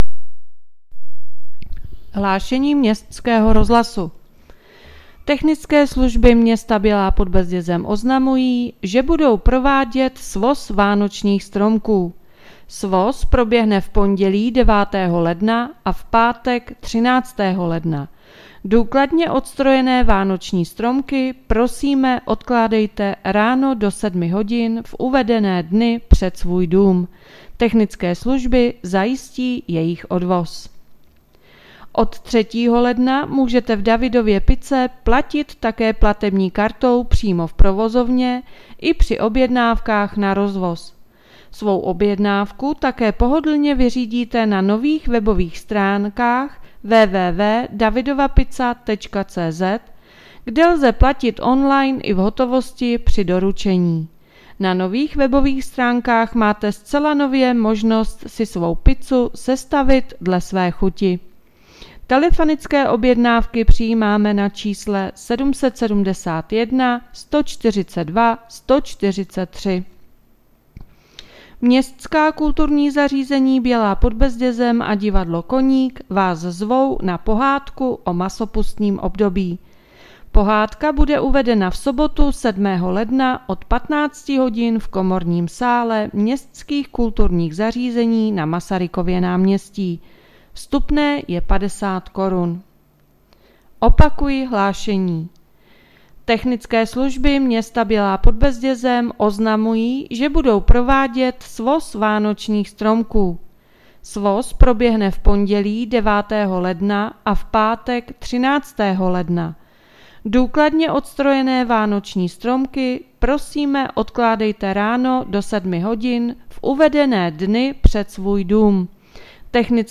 Hlášení městského rozhlasu 6.1.2023